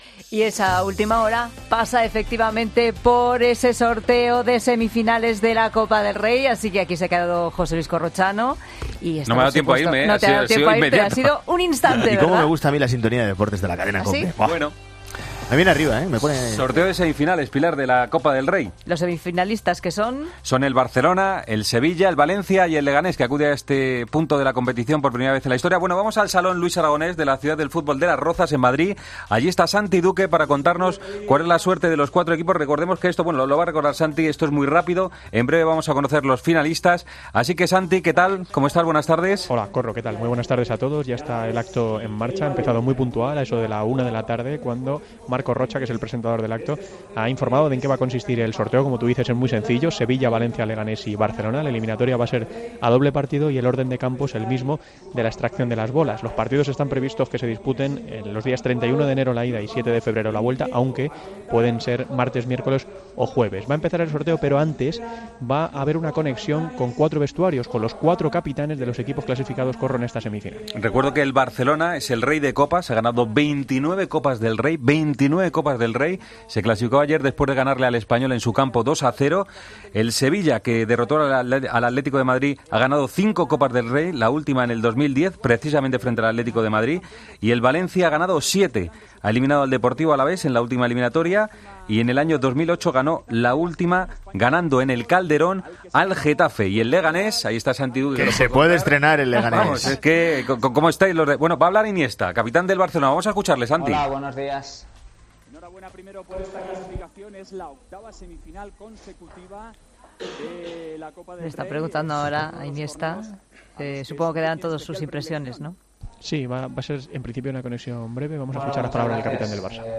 AUDIO: Escucha la retransmisión del sorteo de semifinales de la Copa del Rey de fútbol.